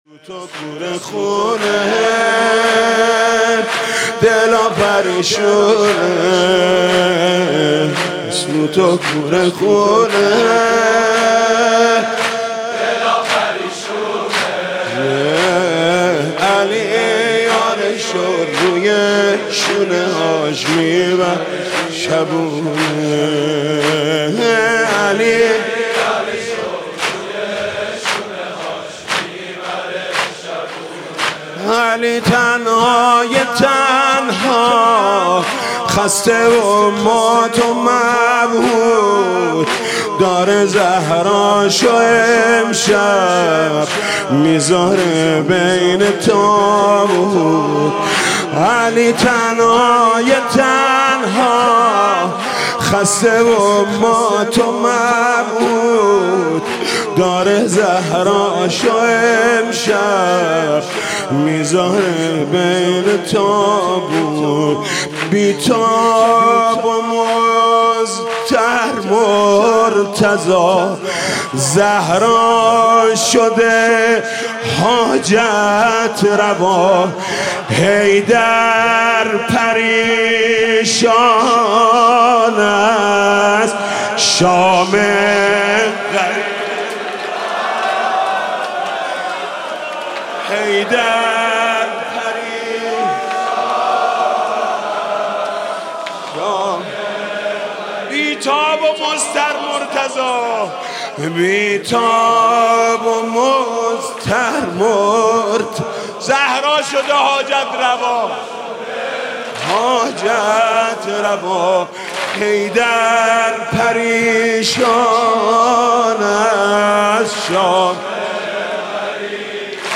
سینه زنی، شهادت حضرت زهرا(س)